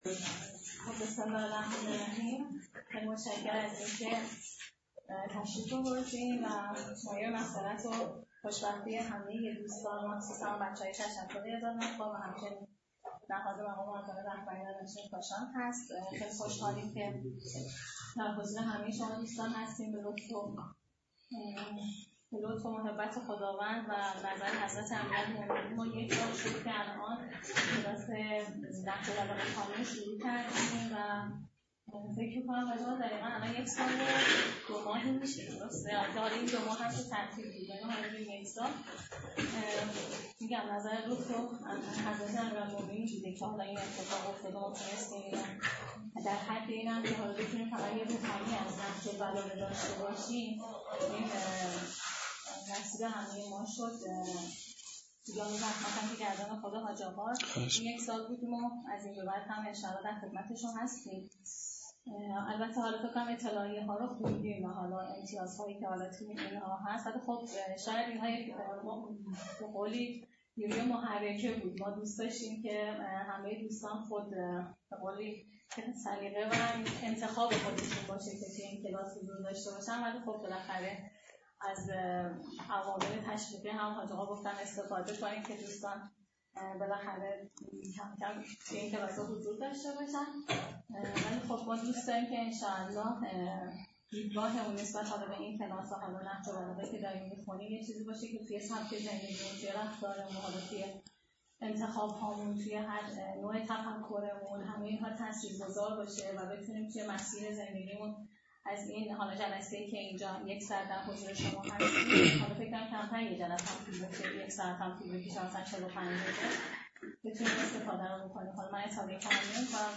جلسه اول کلاس نهج البلاغه یکشنبه ۸-۸-۱۴۰۱